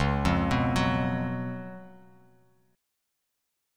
C#11 Chord
Listen to C#11 strummed